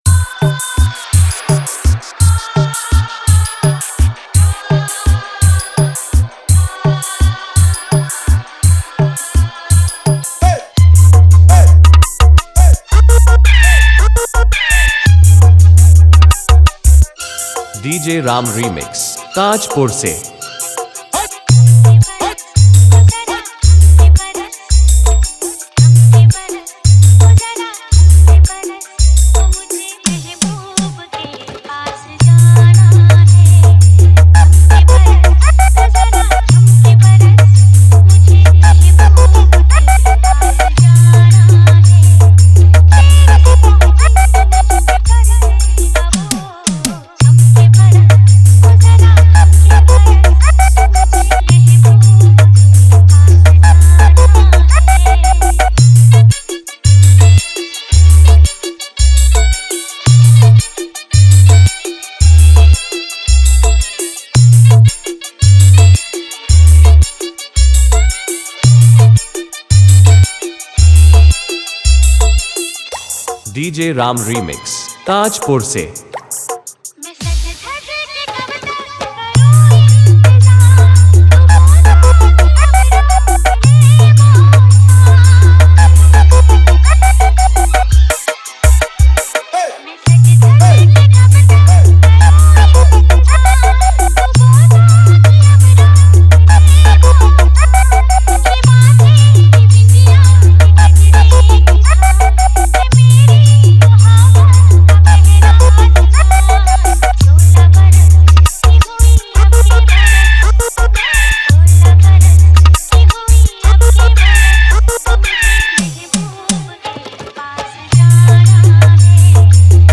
Humming Bass